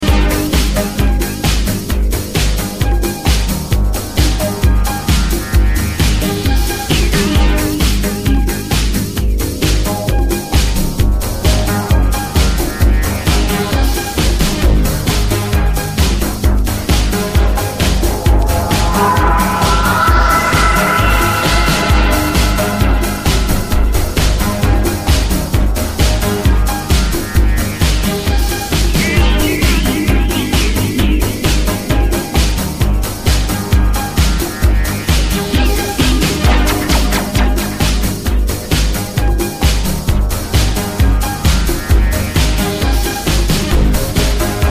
5 premium disco cuts